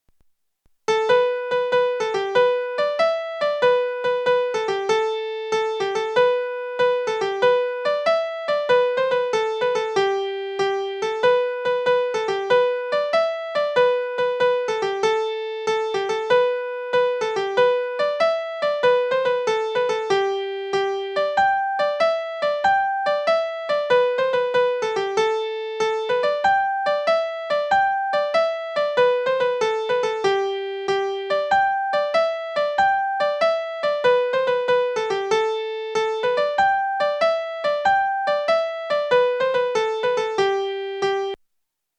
Compare this second version where the long-short pairs are written out in full. The tune is in 6/8 (Jig) time so long-short quaver pairs fall on the 1st and 4th beats in the bar. Quavers at the 3rd and 6th beats are given their full value.
Auntie_Mary_as_played.mp3